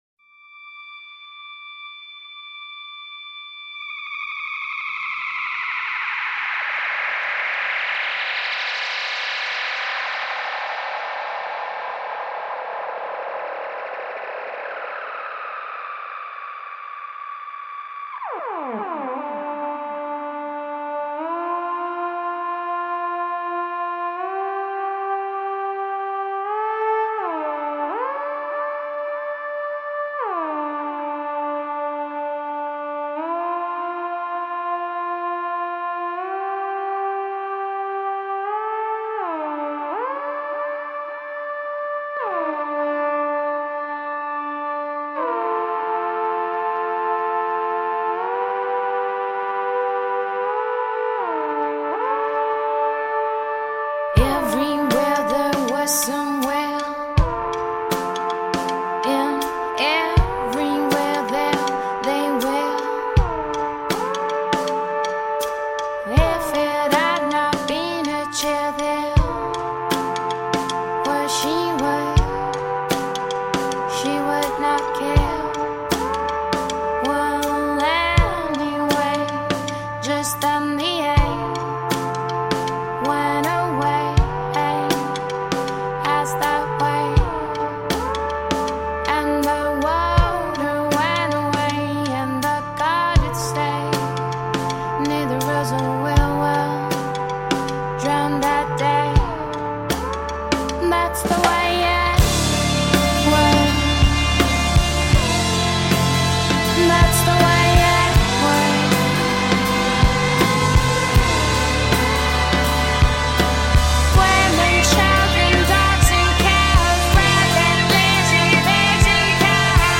Alternative, pop, electronic, rock, trip-hop from italy.
Tagged as: Alt Rock, Folk-Rock, Pop